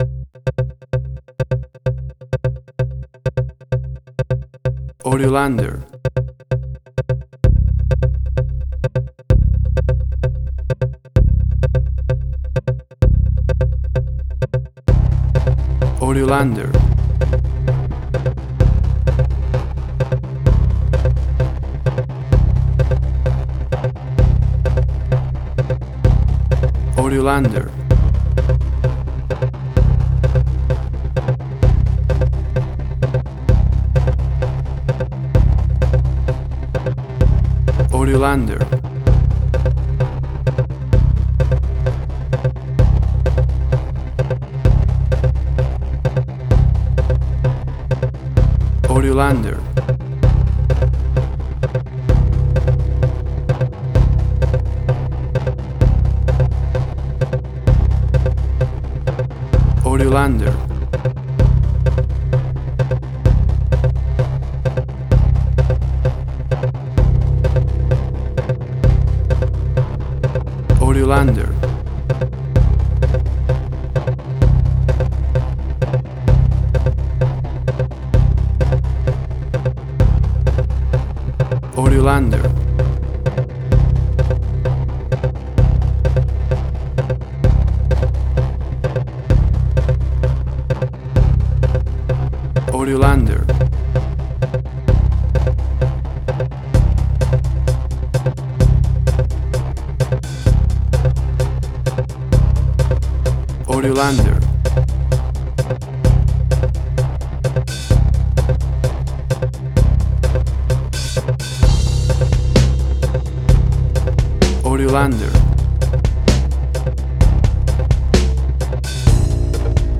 Suspense, Drama, Quirky, Emotional.
Tempo (BPM): 65